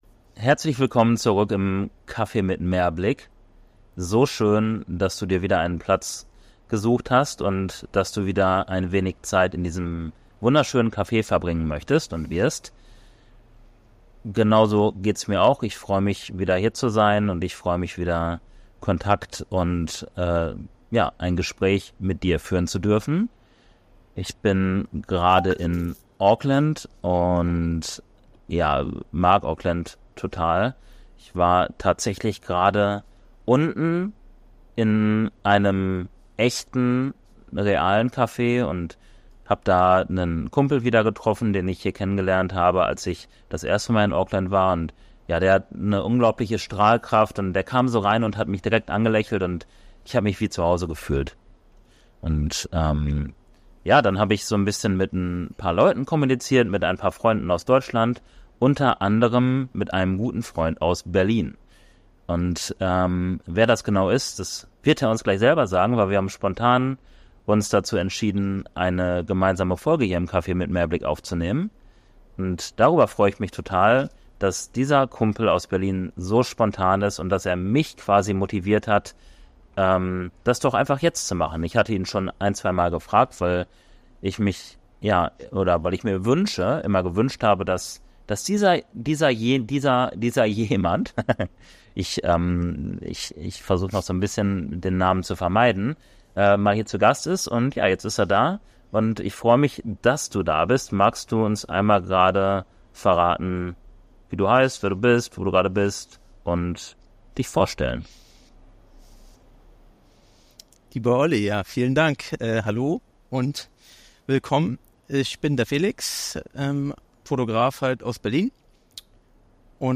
Heute plaudern wir ein wenig über die Fotografie von Menschen und wie er diese interpretiert und umsetzt.